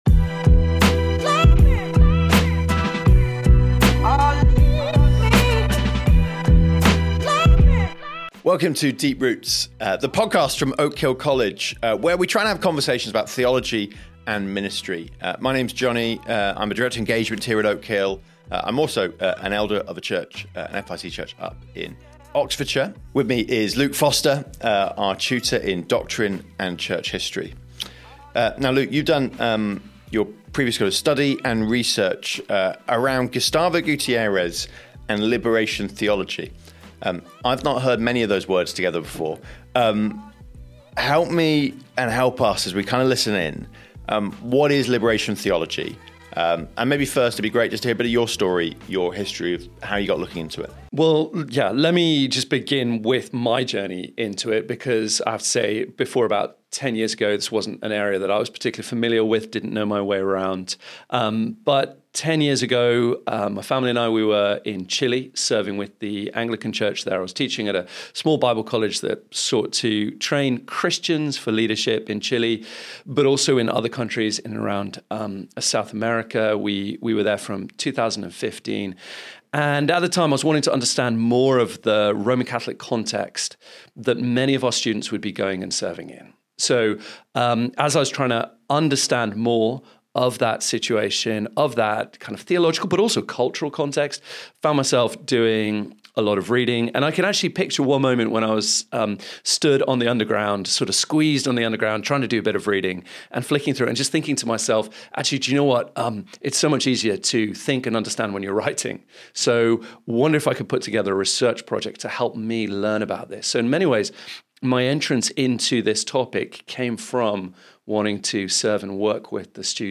A thoughtful, warm, and robust discussion on theology, gospel proclamation, culture, and pastoral care.